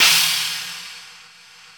Index of /90_sSampleCDs/AMG - Now CD-ROM (Roland)/DRM_NOW! Drums/NOW_K.L.B. Kit 1
CYM KLB CH0K.wav